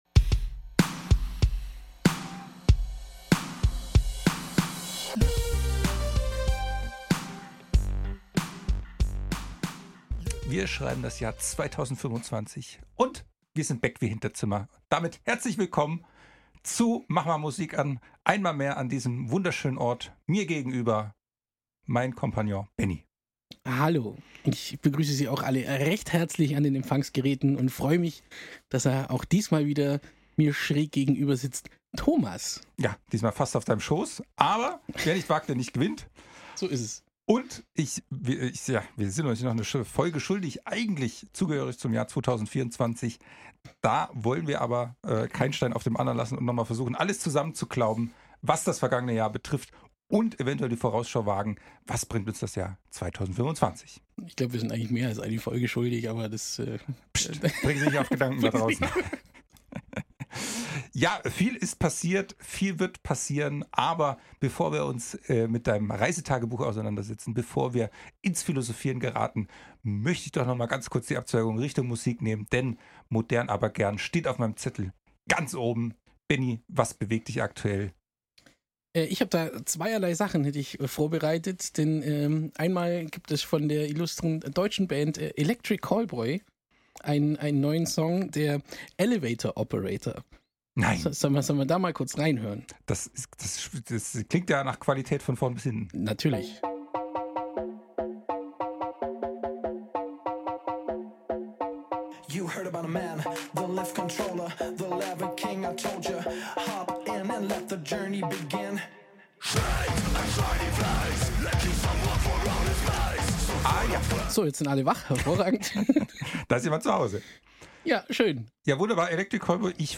Der eine, praktizierender und studierter Musiker und Musikproduzent, mit 20 Jahren Bühnenerfahrung, der andere nix drauf außer Zahnbelag.
Sie diskutieren, streiten, lachen und philosophieren über Themen aus der Musikwelt und ihre persönlichen Hörgewohnheiten.